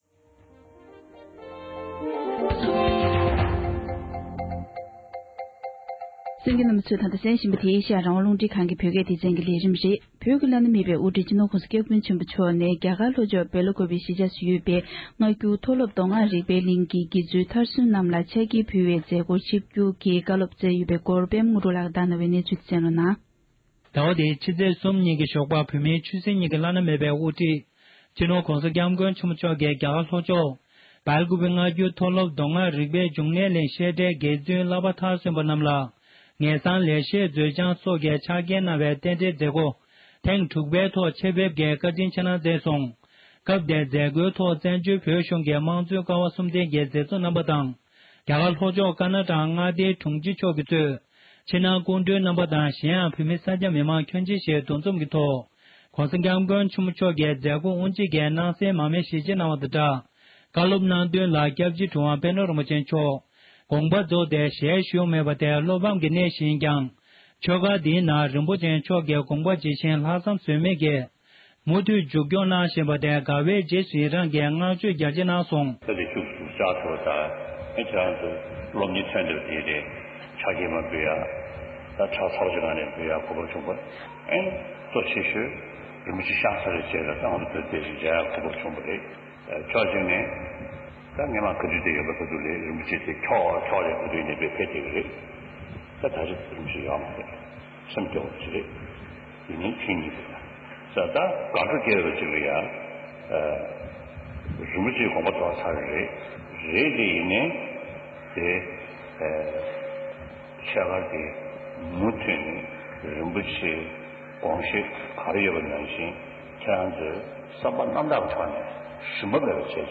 ས་གནས་ས་ཐོག་ནས་བཏང་འབྱོར་བྱུང་བའི་གནས་ཚུལ་ཞིག་ལ་གསན་རོགས༎